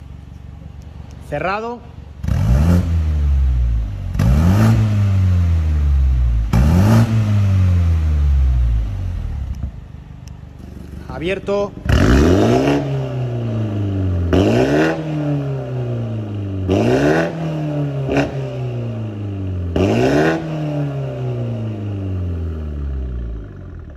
BMW M135i con intermedio recto sound effects free download
BMW M135i con intermedio recto y final con válvulas, brutal